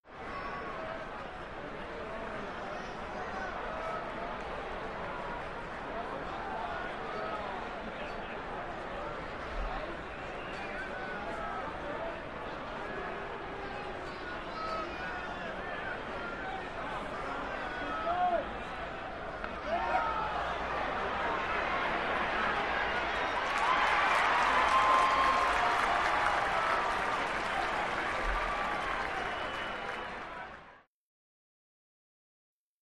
Arena Crowd; Crowd, Small, Active, Boos To Light Cheers.